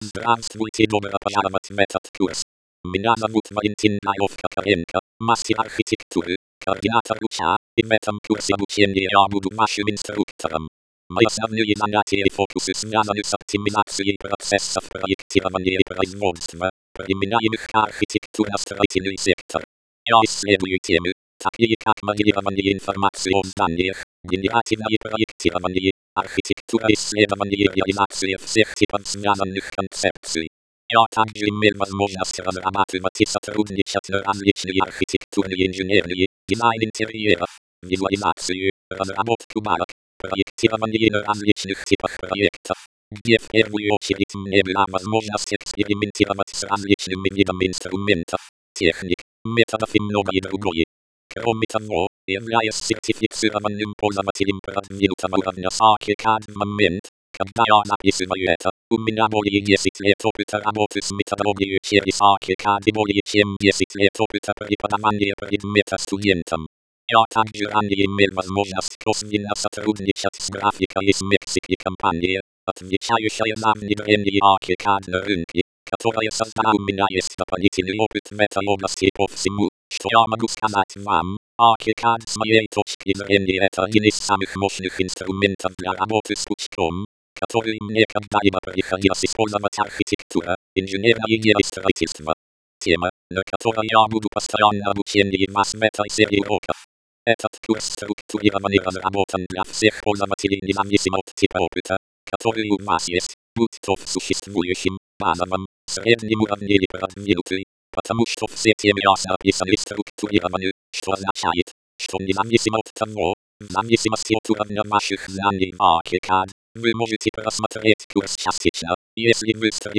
Режим: Видео + озвучка (Русский)